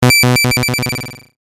描述：低质量的噪音录音用老式合成器制作的随机模拟挤压声和哔哔声
标签： 类似物 BIP 哔哔声 FX
声道立体声